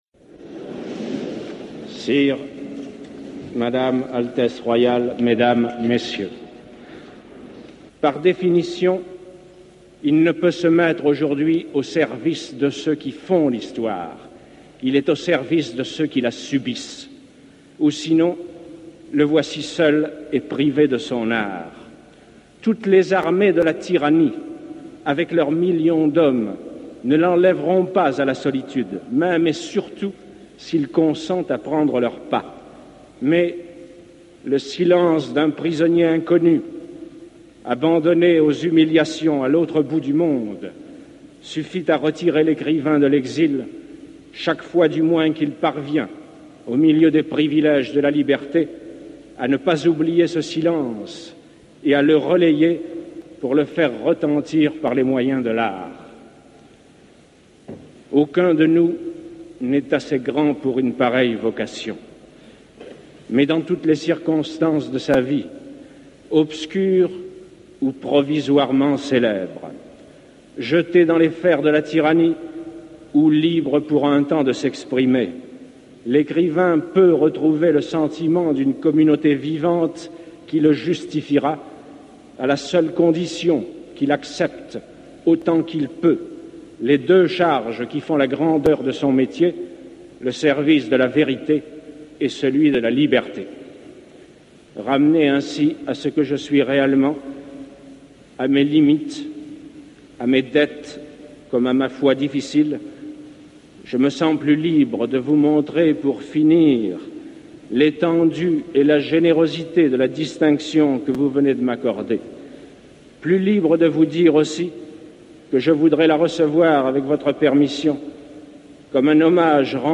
Albert-Camus_Nobel-prize-speech-1957.mp3